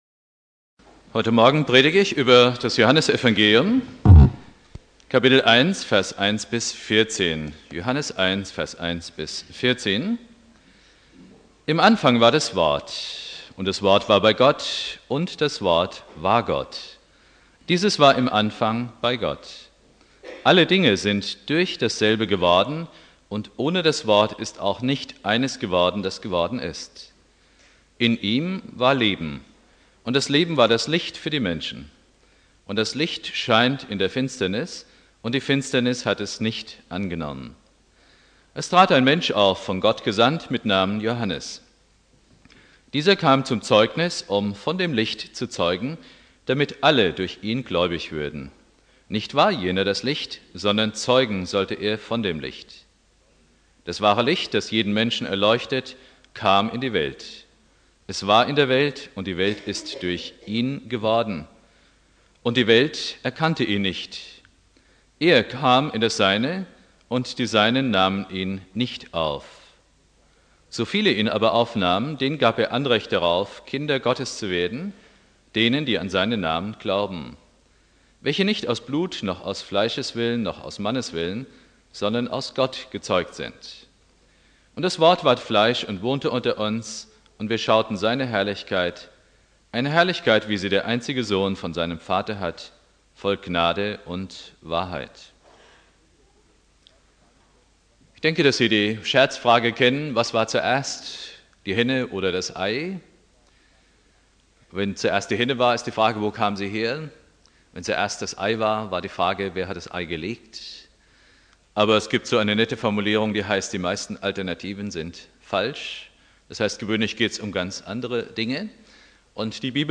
2.Weihnachtstag